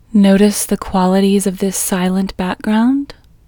QUIETNESS Female English 16
Quietness-Female-16-1.mp3